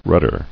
[rud·der]